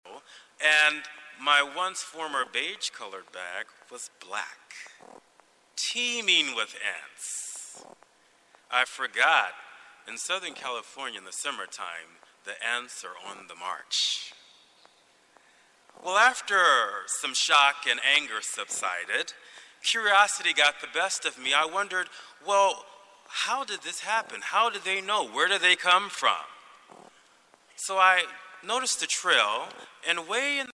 You'll hear a reduction of the buzzing, but you might not like the effect overall.
eq_to_mask_some_buzz.mp3